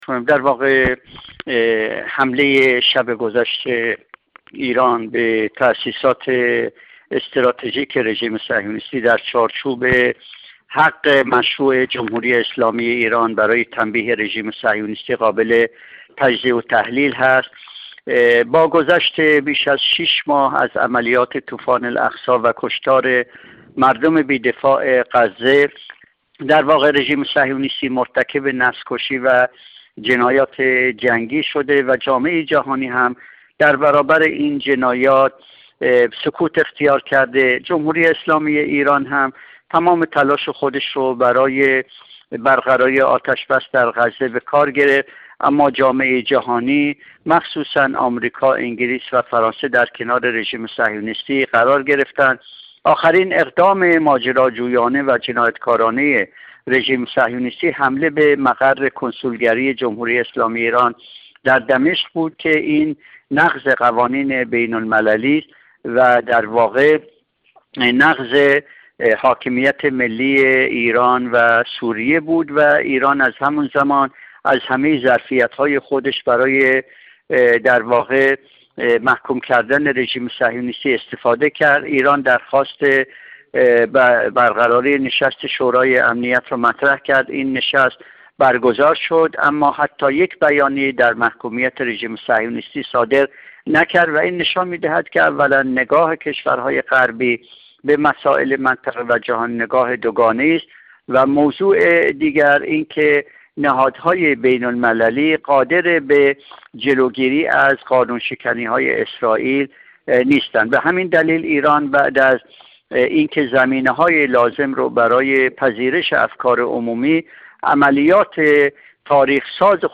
کارشناس مسائل منطقه
گفت‌وگو با ایکنا